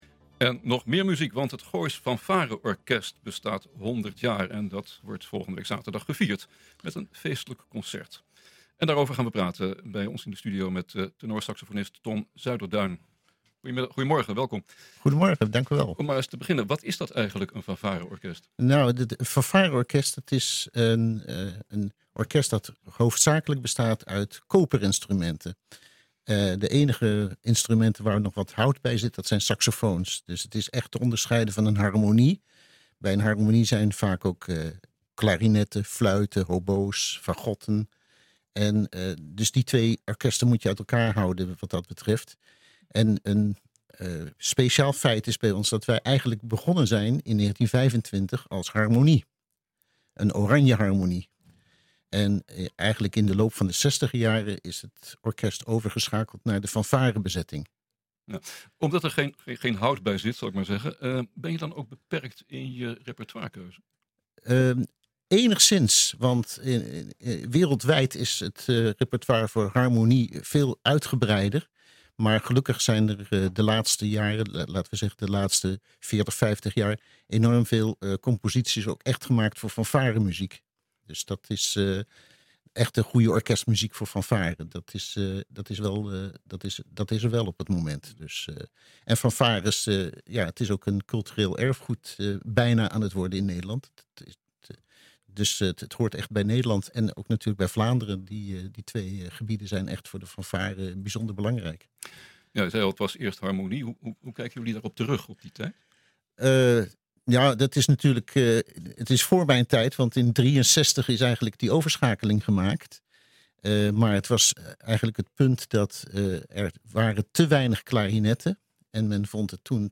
U luistert nu naar NH Gooi Zaterdag - Jubileumconcert van Gooisch Fanfare Orkest
jubileumconcert-van-gooisch-fanfare-orkest.mp3